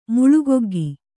♪ muḷugoggi